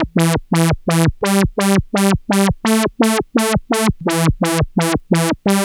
Synth 23.wav